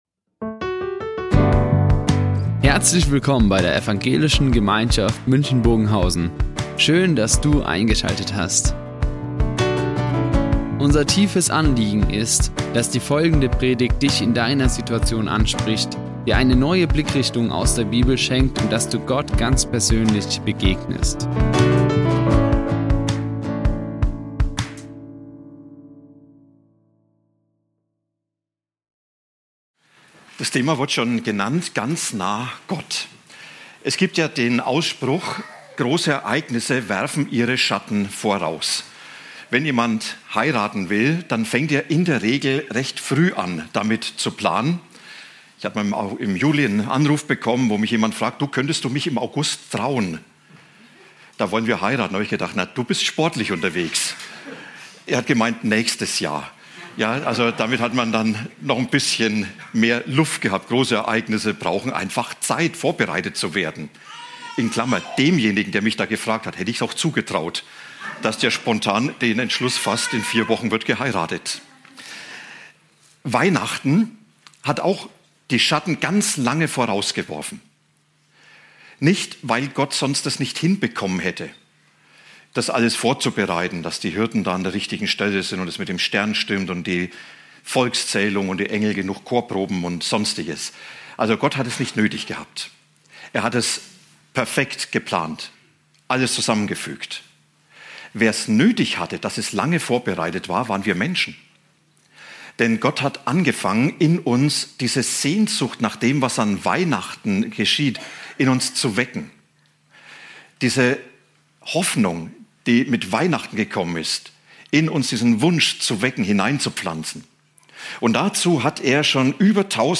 Heilig Abend - Ganz nah Gott | Predigt Hesekiel 37,24-28 ~ Ev. Gemeinschaft München Predigten Podcast